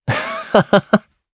Esker-Vox_Happy2_jp_c.wav